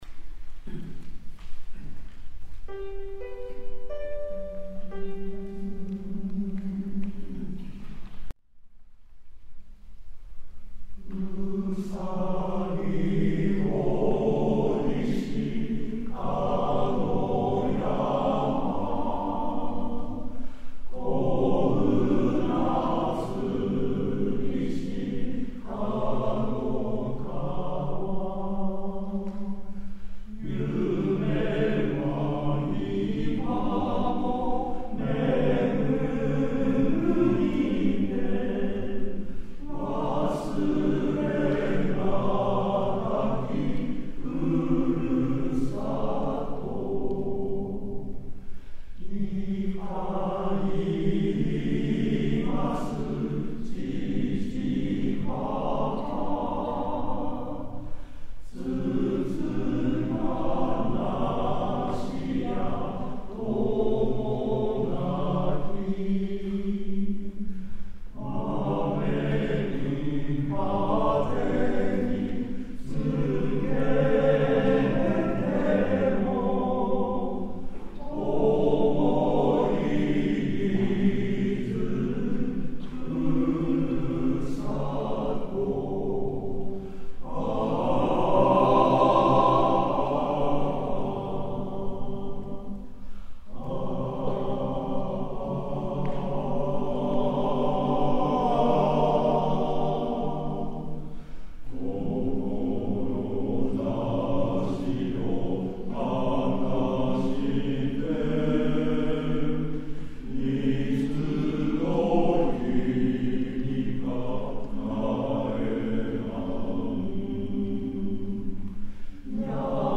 世田谷合唱祭
会場 世田谷区民会館